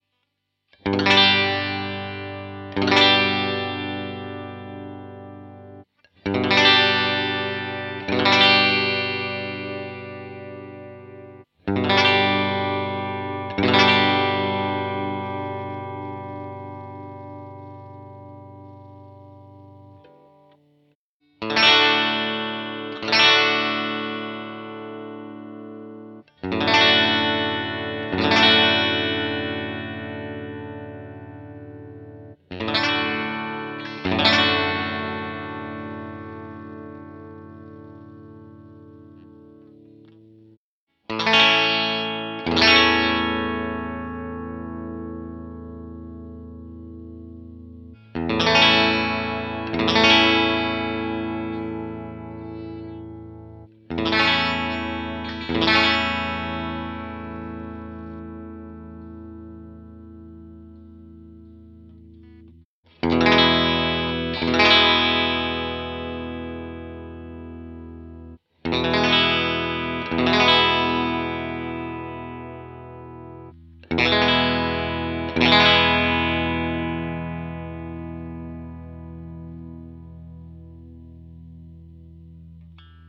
These are pretty boring examples, I did just strumm some chords. Each chord is strummed twice, then the guitar changes. All samples are done with the same cable and for sure its the same amp, because I recorded without amp with a Line6 Pod Xt and the simulation is done on the PC by the Line6 Soundfarm plugin.
Bridge
So, always first is the Squier CV, then the G&L Legacy and at last the Hohner with the Fender Texas Specials.